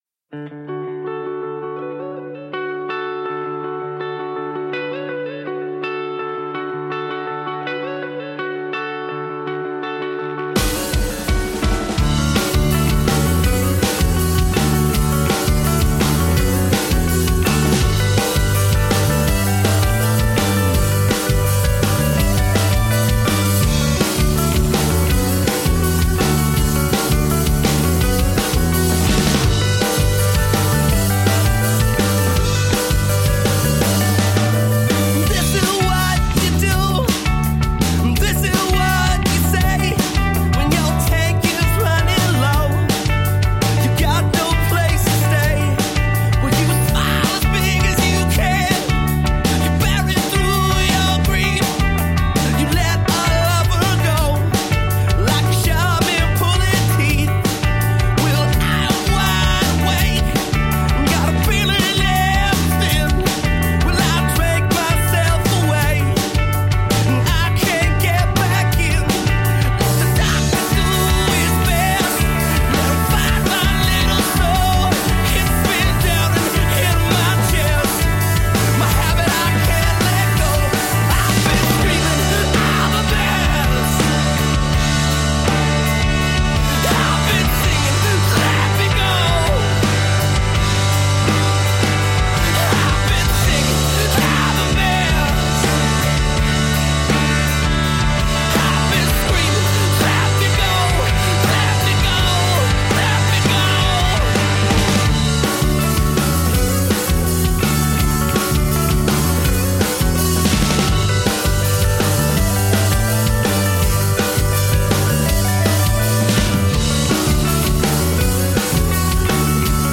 Easy-to-listen-to pop rock with a fresh attitude.
Tagged as: Alt Rock, Pop, Rock, Indie Rock